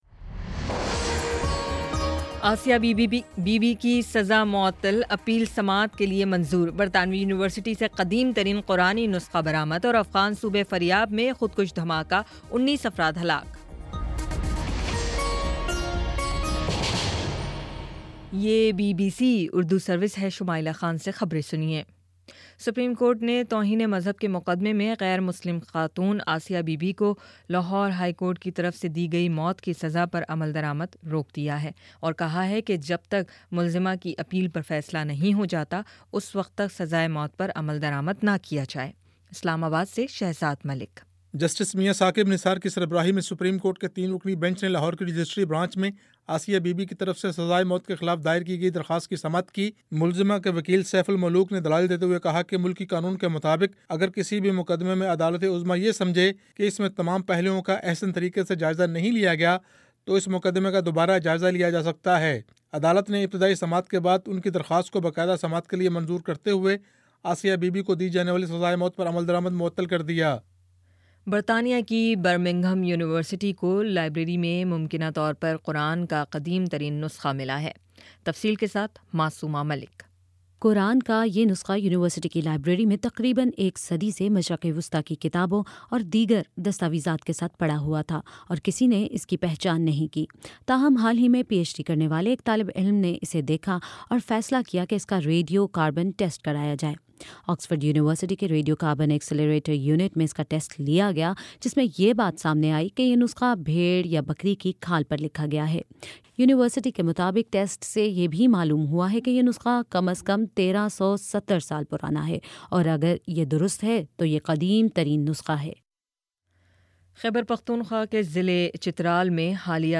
جولائی 22: شام پانچ بجے کا نیوز بُلیٹن